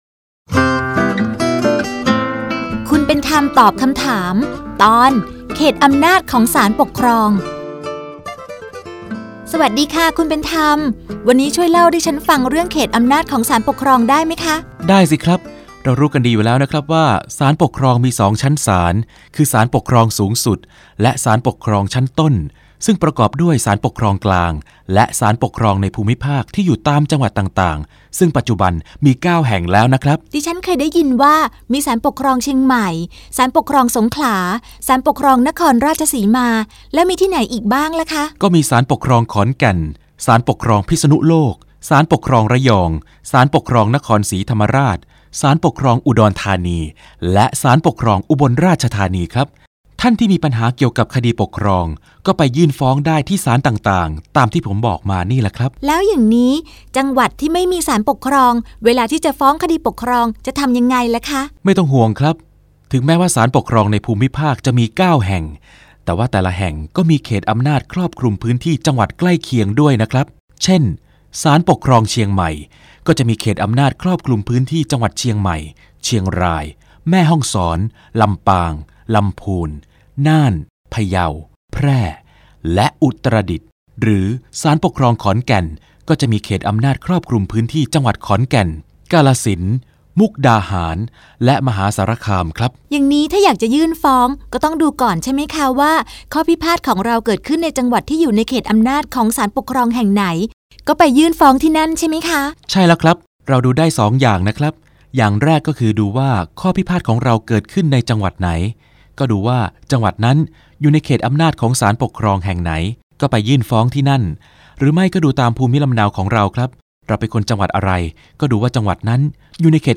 สารคดีวิทยุ ชุดคุณเป็นธรรมตอบคำถาม ตอน เขตอำนาจของศาลปกครอง